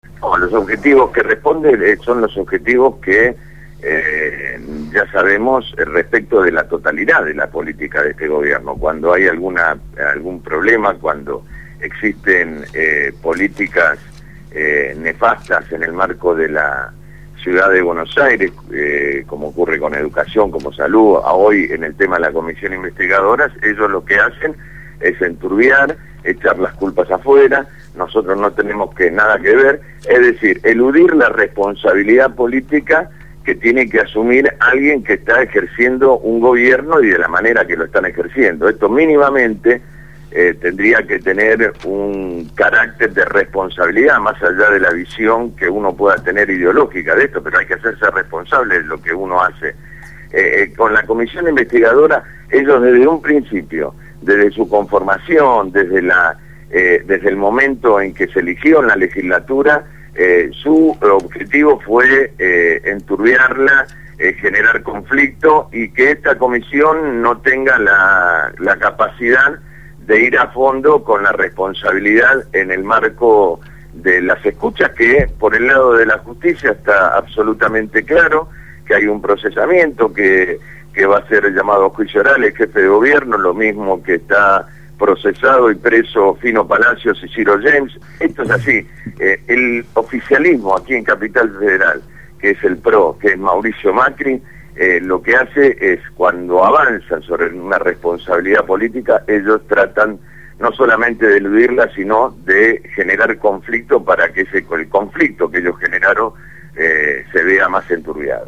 entrevistaron al Legislador del EPV Francisco «Tito» Nenna